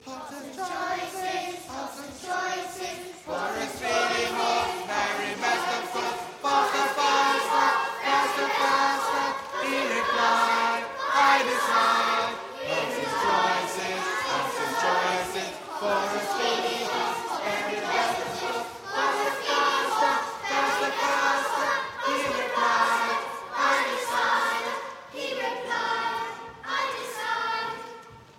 Singing History Concert 2016: Hobson's 2